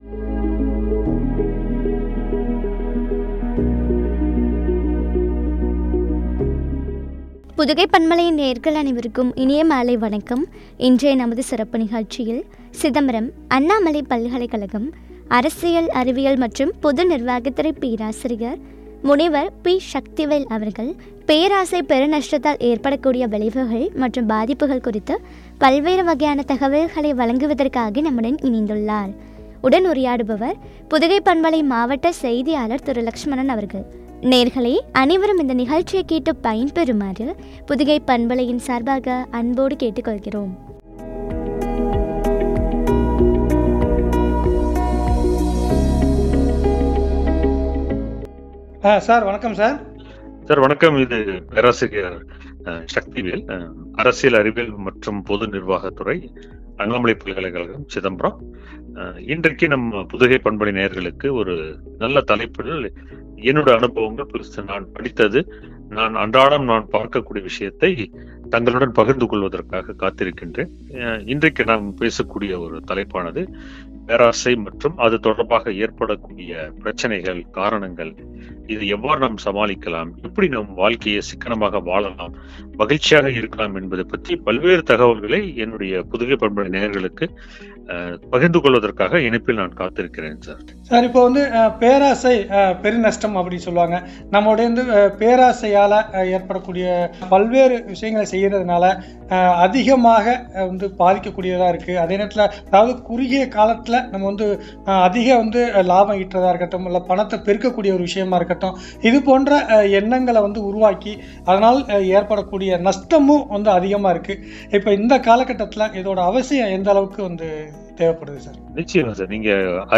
“பேராசை பெரு நஷ்டம்” குறித்து வழங்கிய உரையாடல்.